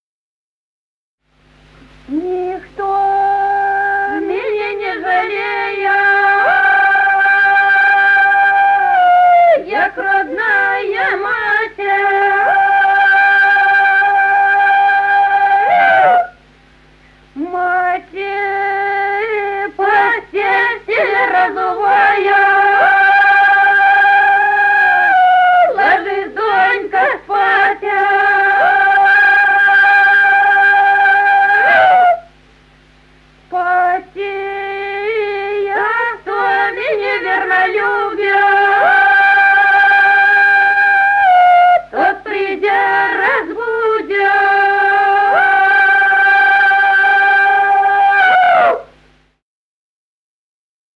Народные песни Стародубского района «Никто меня не жалея», весняная девичья.